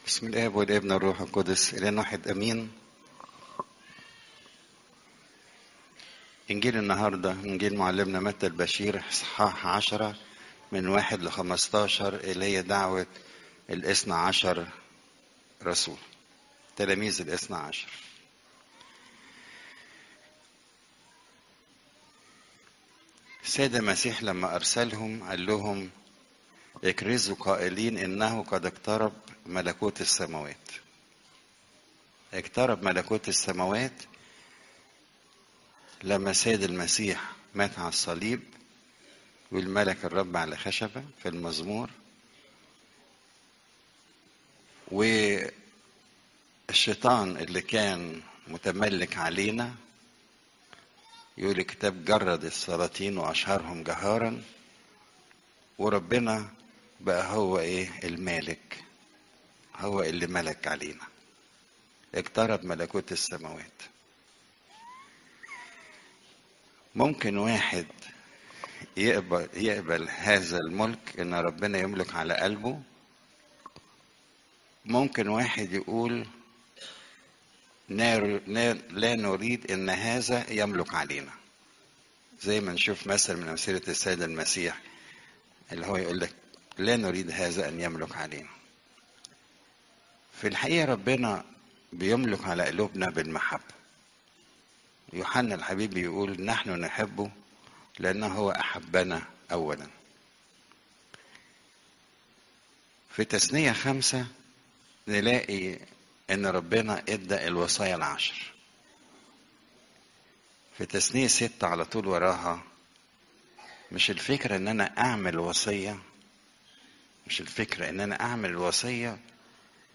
عظات قداسات الكنيسة صوم الميلاد (مت 10 : 1 - 15)